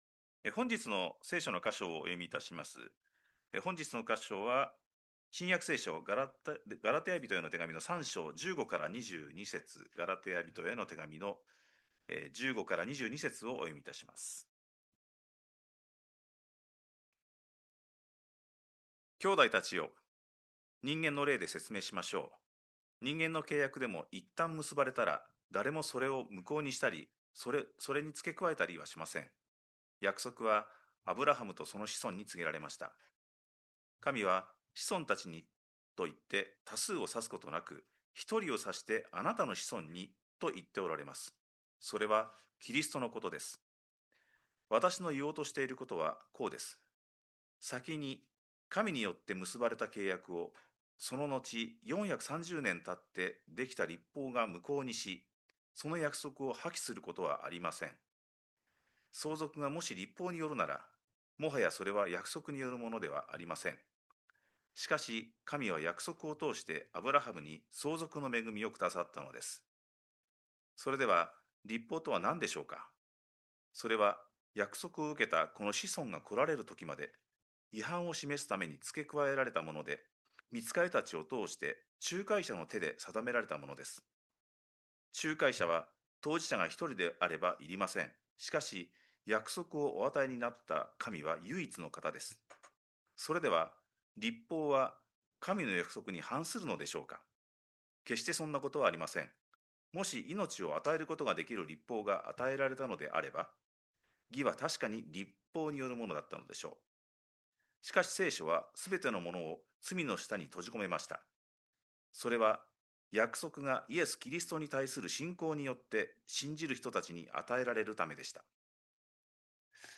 2026年3月8日礼拝 説教 「仲介者モーセ？」